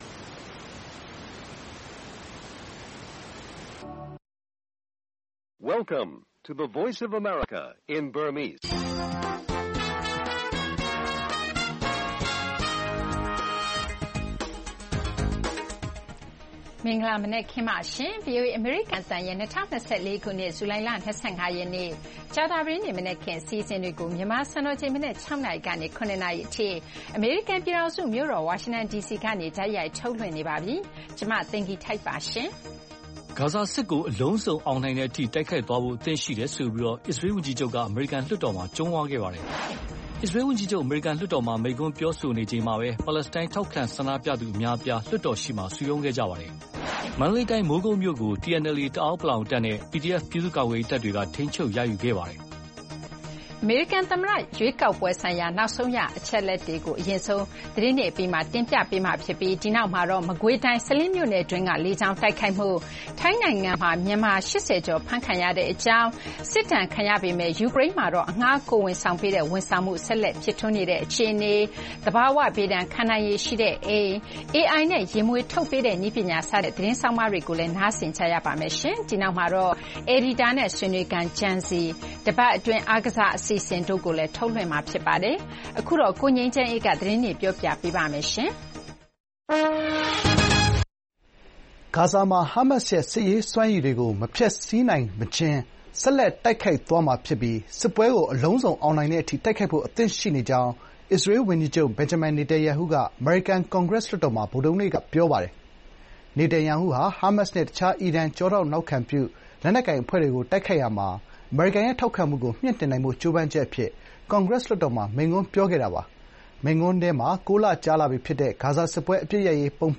ဗွီအိုအေမြန်မာနံနက်ခင်း(ဇူလှိုင်၂၅၊၂၀၂၄) လုံးဝအနိုင်ရတဲ့အထိတိုက်ပွဲဝင်မယ်လို့ အစ္စရေးဝန်ကြီးချုပ်ကြုံးဝါး၊ မိုးကုတ်မြို့ကို TNLA နဲ့ PDF ပူးပေါင်းတပ်တွေ ထိန်းချုပ် စတဲ့သတင်းတွေနဲ့ အပတ်စဉ်စဉ်အစီအစဉ်တွေအပြင် သမ္မတ Biden ရဲ့ အခုညပိုင်း မိန့်ခွန်းကောက်နုတ်ချက်တချို့ တင်ဆက်သွားပါမယ်။